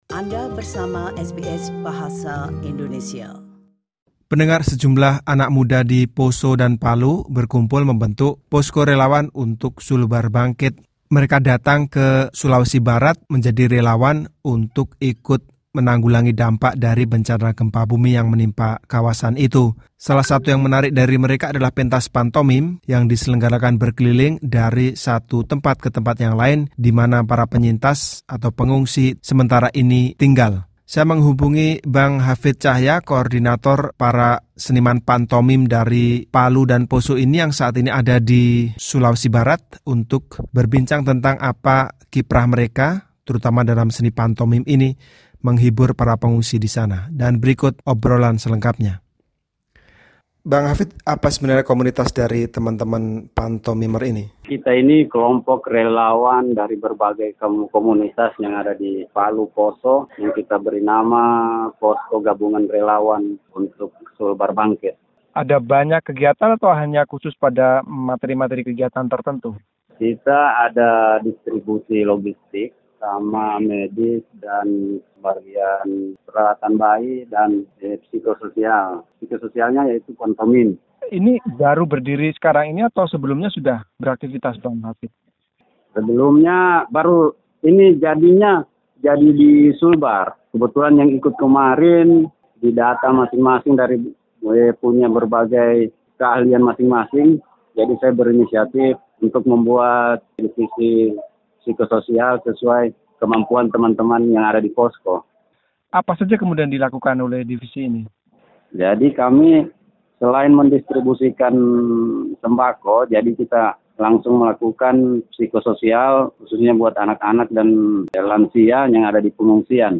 Ikuti wawancara berikut untuk mengetahui kiprahnya bersama Posko Gabungan Relawan untuk Sulbar Bangkit.